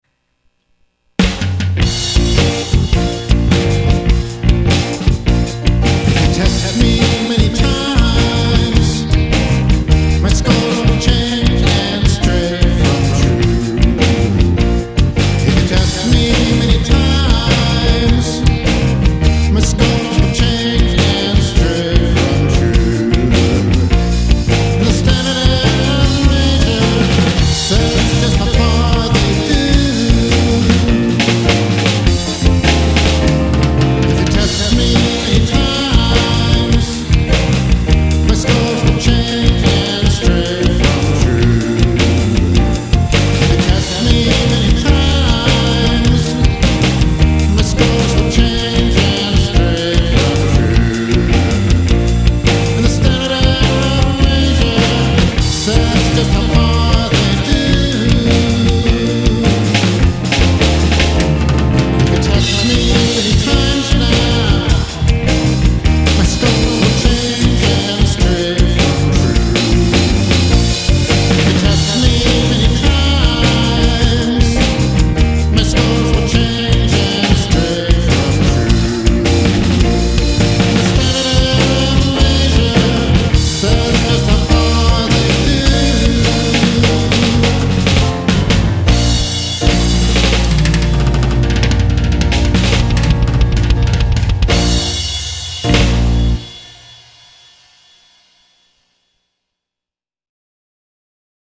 This is a page of stat music.